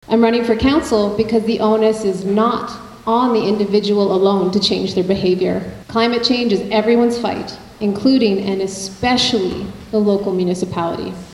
The nominees gathered at McNab School September 22nd for an All-Candidate Forum, hosted by the Greater Arnprior Chamber of Commerce.